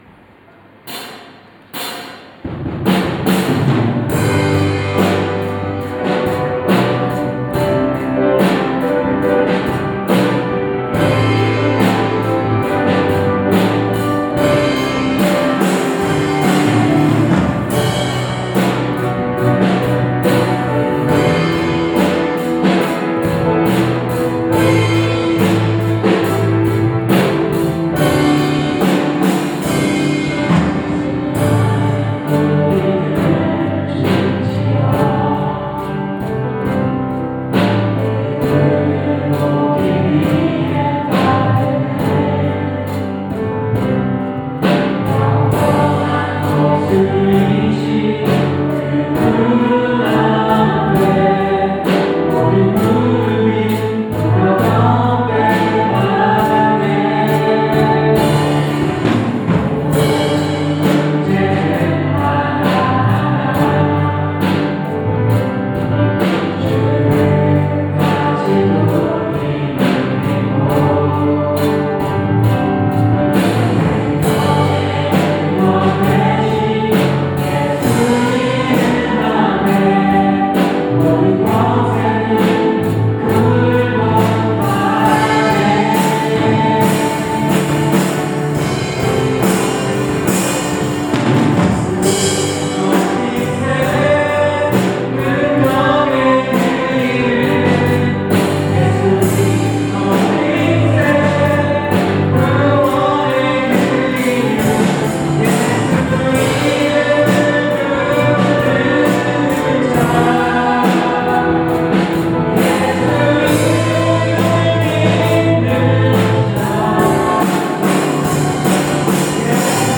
2024년 01월 07일 주일찬양